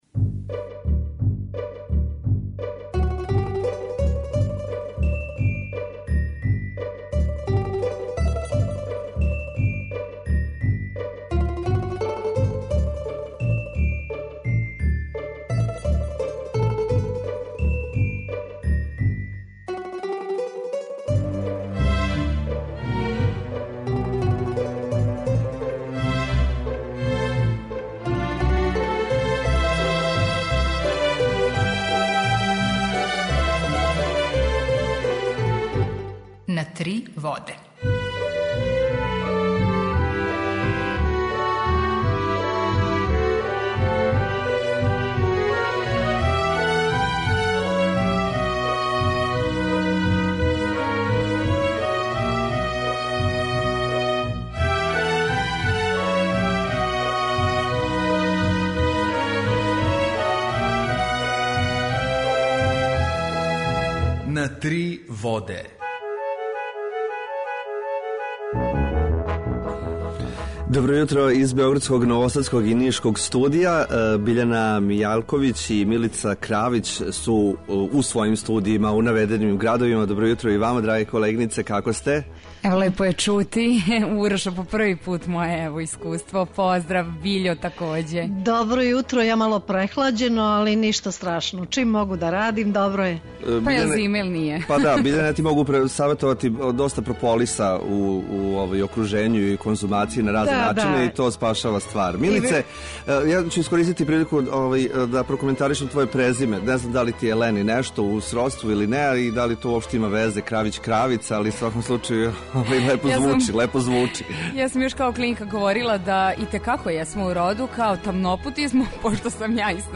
У два сата биће и добре музике, другачије у односу на остале радио-станице.